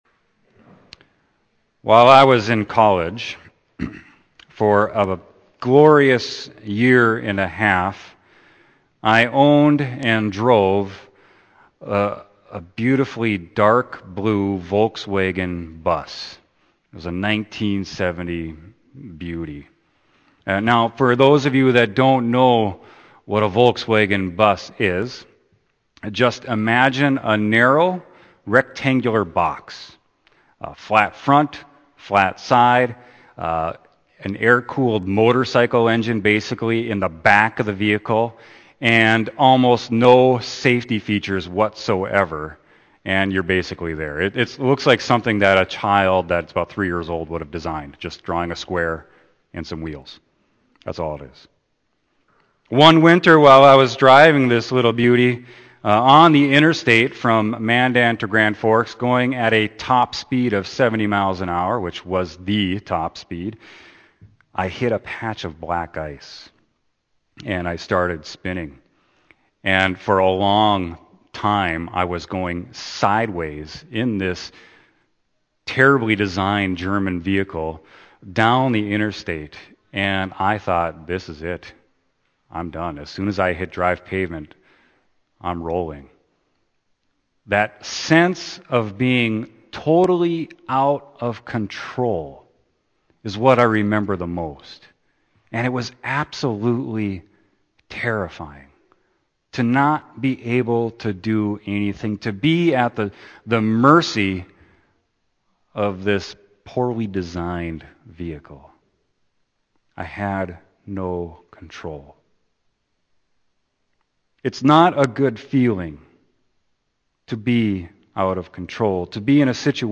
Scriptures: John 18:1-12, 28-40; 19:1-42 Sermon: John 18.1-12